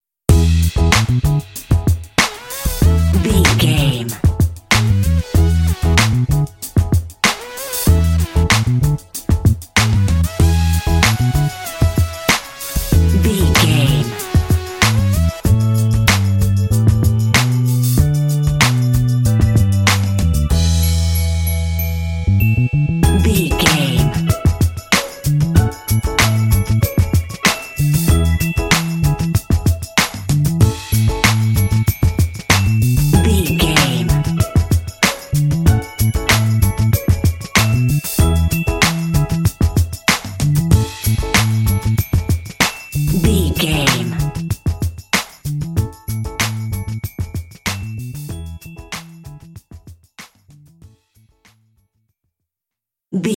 Uplifting
Mixolydian
funky
happy
bouncy
groovy
synthesiser
drums
bass guitar
electric piano
strings
r& b
Funk